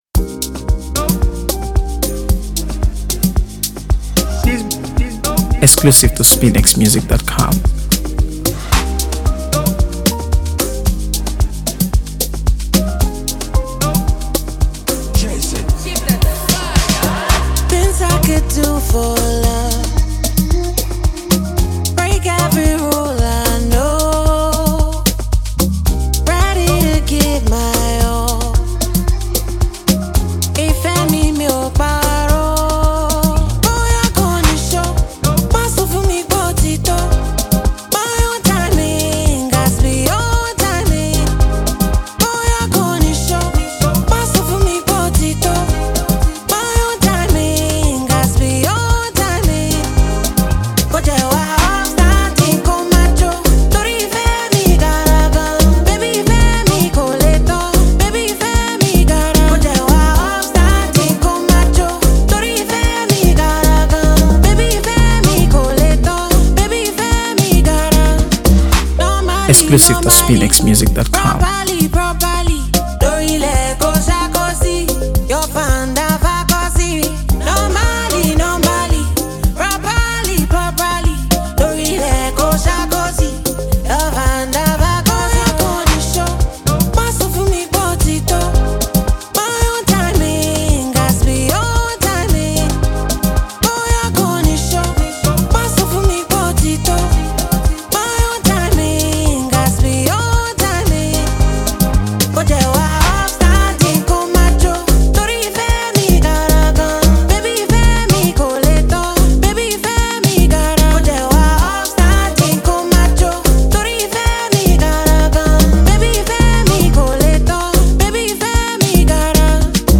AfroBeats | AfroBeats songs
Through his distinct fusion of soulful rhythms and Afro-pop